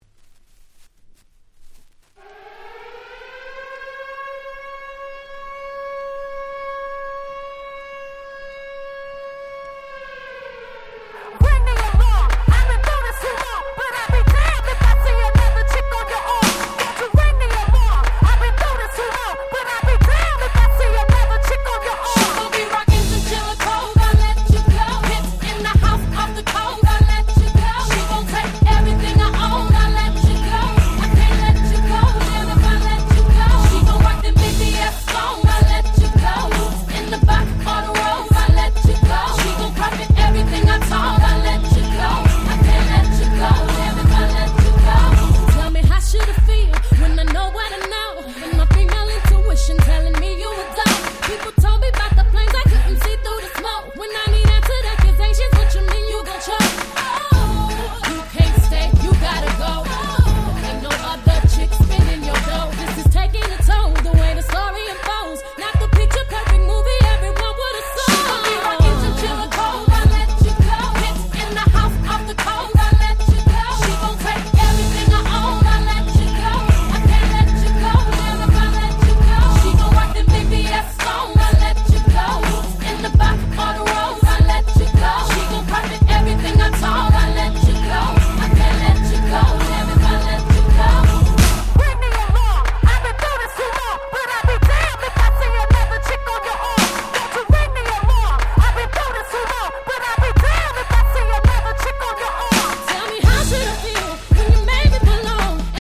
06' Super Hit R&B !!
レゲエ